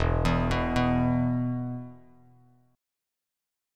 E5 Chord
Listen to E5 strummed